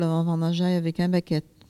Bois-de-Céné
collecte de locutions vernaculaires